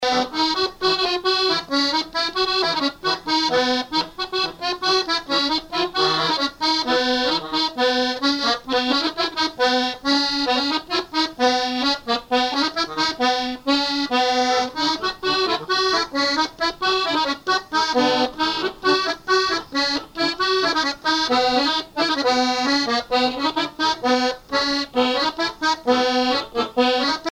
branle : courante, maraîchine
musique à danser à l'accordéon diatonique
Pièce musicale inédite